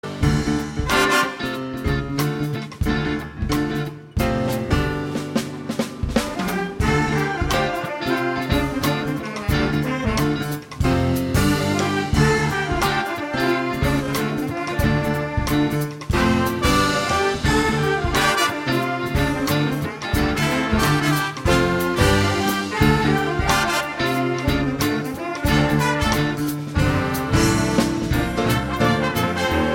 Hier finden Sie einige Hörproben aus unserem „20-Jahre Vincents Bigband“-Konzert, die aus lizenzrechtlichen Gründen nur eine Länge von 30 Sekunden haben.